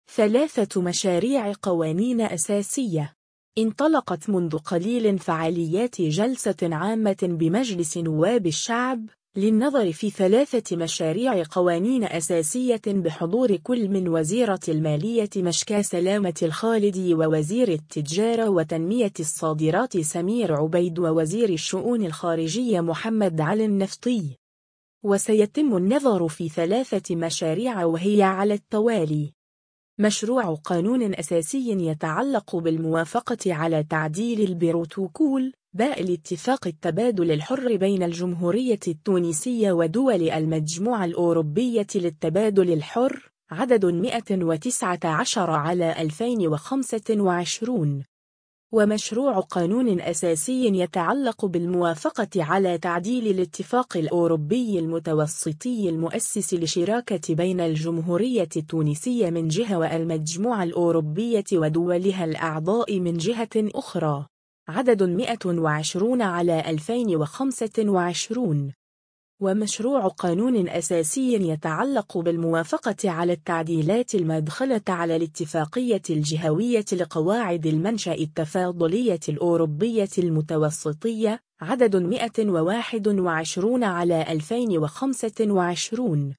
الآن : جلسة عامة بالبرلمان للنّظر في مشاريع 3 قوانين أساسية (فيديو)
انطلقت منذ قليل فعاليات جلسة عامة بمجلس نواب الشعب، للنّظر في 3 مشاريع قوانين أساسية بحضور كل من وزيرة المالية مشكاة سلامة الخالدي ووزير التجارة وتنمية الصادرات سمير عبيد ووزير الشؤون الخارجية محمد علي النفطي.